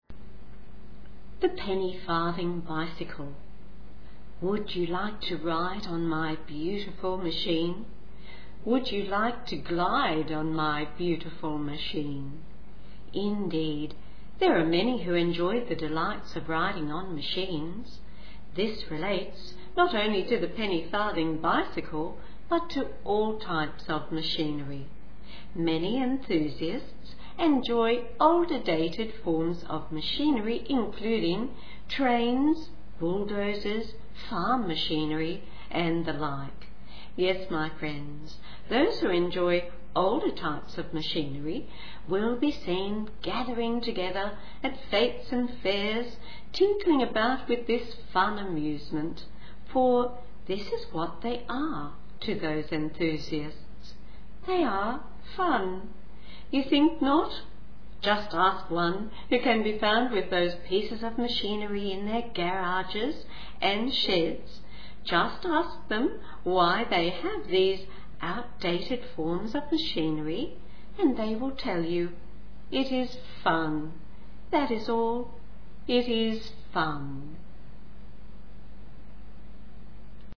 Penny-farthing Bicycle   (Listen while you read)